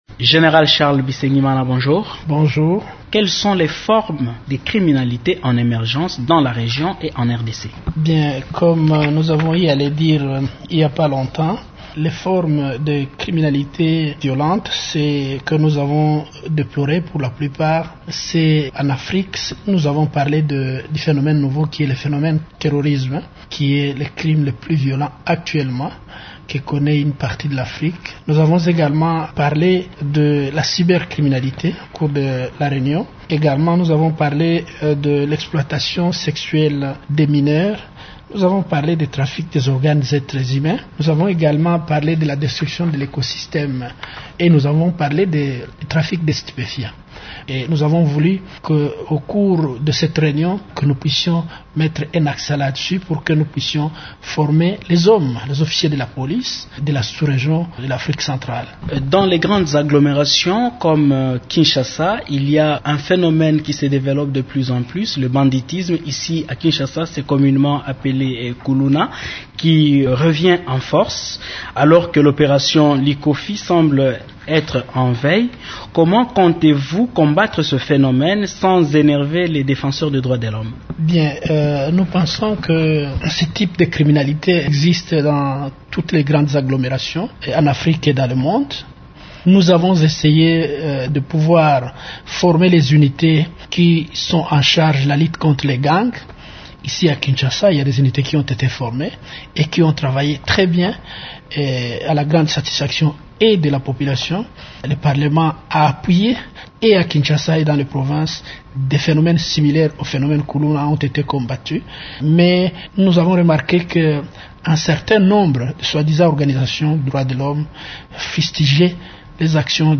Charles Bisengimana parle de la stratégie de coopération adoptée lors de cette réunion :